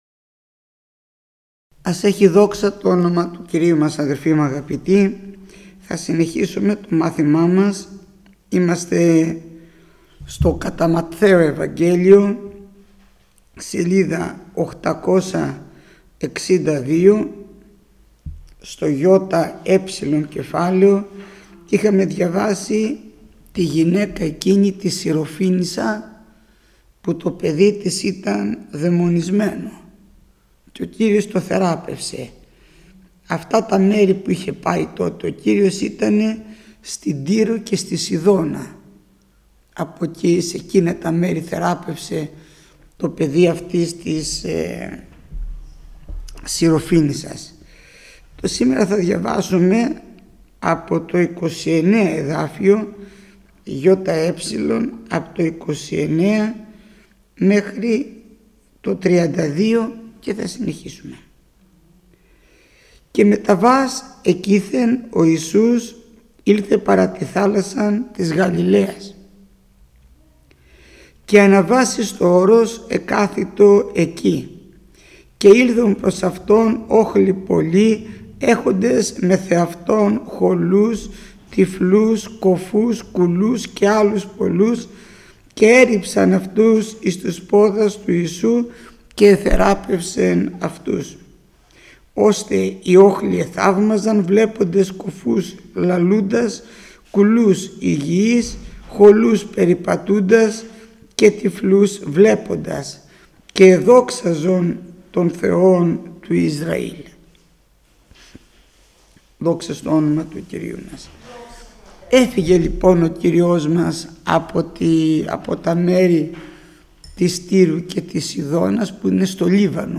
Μάθημα 454ο Γεννηθήτω το θέλημά σου